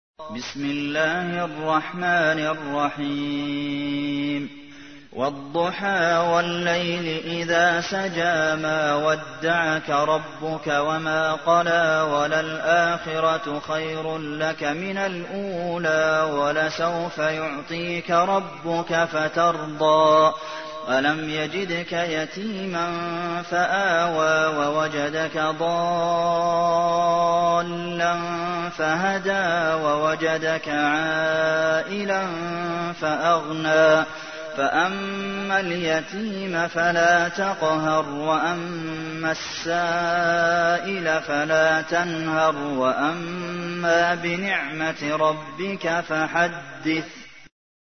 تحميل : 93. سورة الضحى / القارئ عبد المحسن قاسم / القرآن الكريم / موقع يا حسين